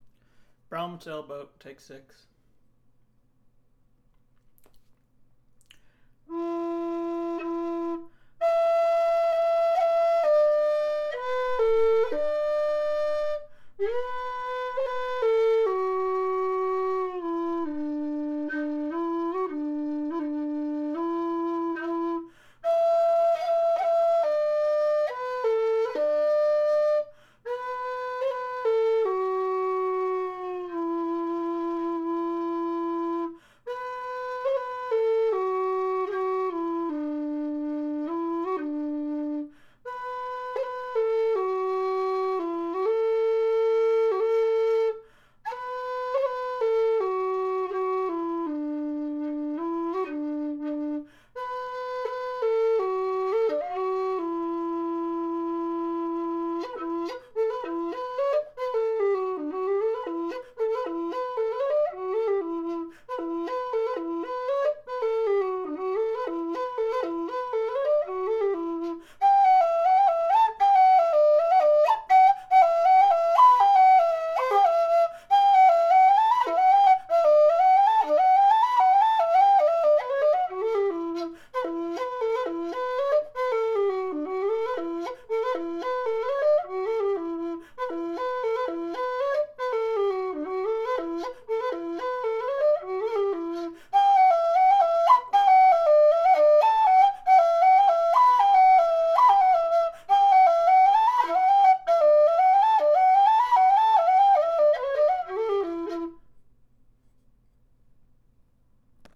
Scottish Air and highland on low whistle.